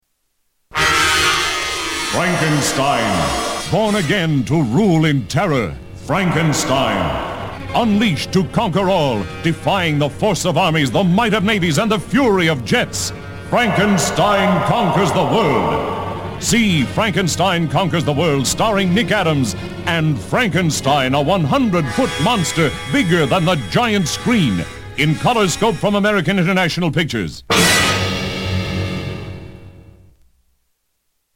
Radio Spots!
The spots are really good and the announcer brings excitement to the offerings.